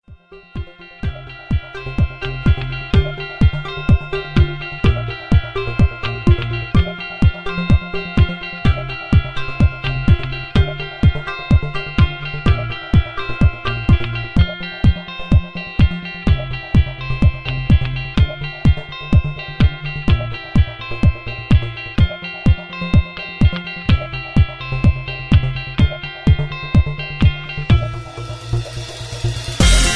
Karaoke Mp3 Backing Tracks